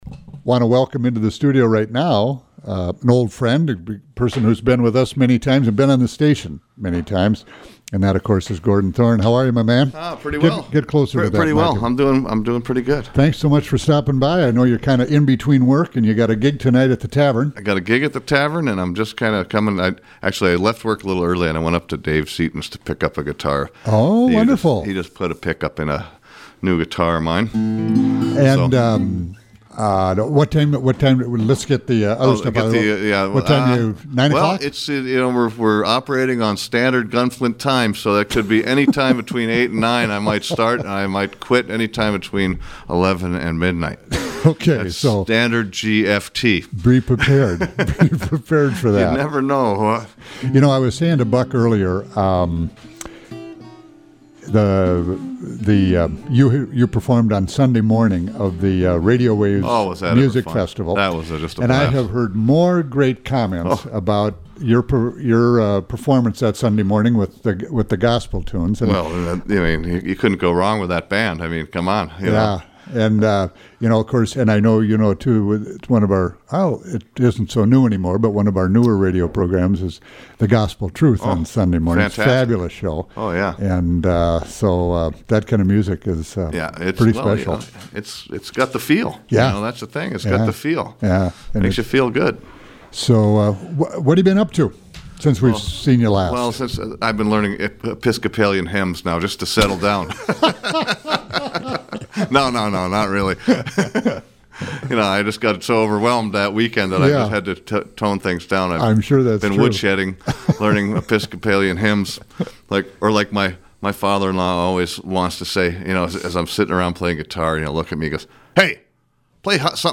bluesman and finger-picker
Listen in for chat, laughter, and some really great train songs. Program: Live Music Archive The Roadhouse